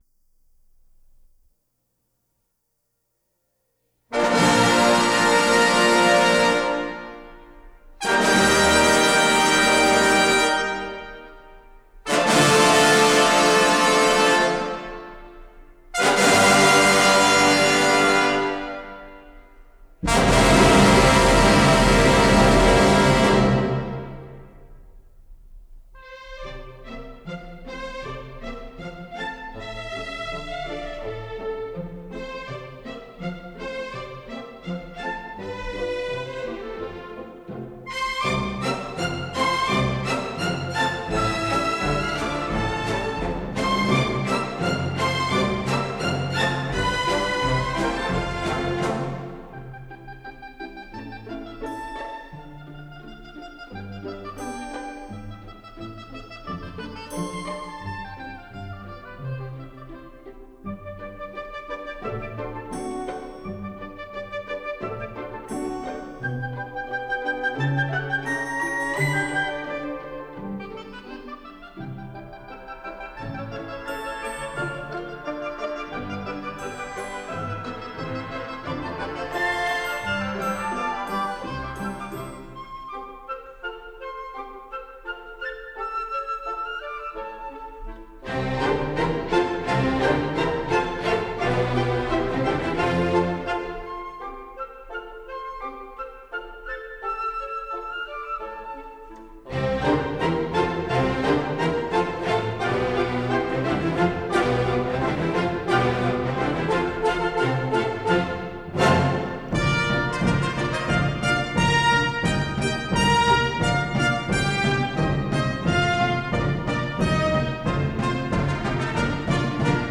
Recorded in Mozart Hall, Vienna